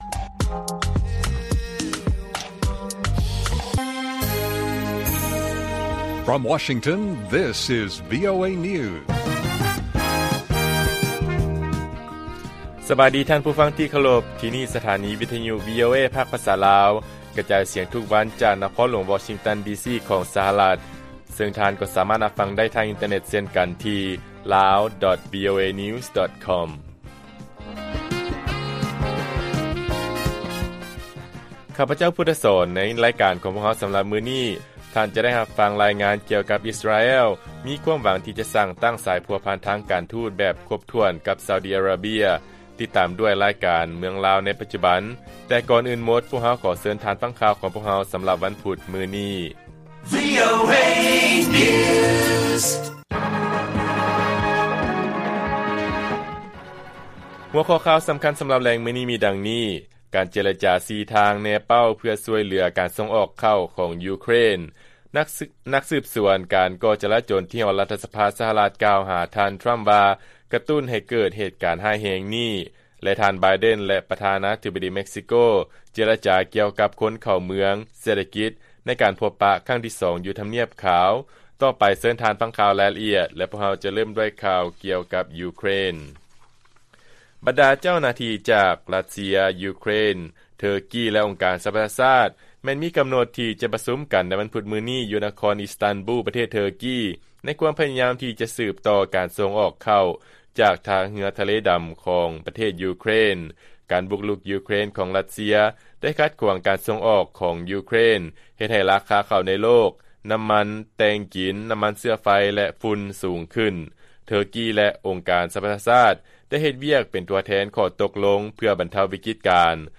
ວີໂອເອພາກພາສາລາວ ກະຈາຍສຽງທຸກໆວັນ, ຫົວຂໍ້ຂ່າວສໍາຄັນໃນມື້ນີ້ມີ: 1. ການເຈລະຈາສີ່ທາງ ເພື່ອຊ່ວຍເຫຼືອການສົ່ງອອກເຂົ້າຂອງ ຢູເຄຣນ, 2. ຄະນະສືບສວນການກໍ່ຈາລະຈົນກ່າວວ່າ ທ່ານ ທຣຳ ຄືຜູ້ຜັກດັນເຮັດໃຫ້ເກີດເຫດການ, ແລະ 3. ທ່ານ ໄບເດັນ ແລະ ປະທານາທິບໍດີ ເມັກຊິໂກ ເຈລະຈາຢູ່ທຳນຽບຂາວ.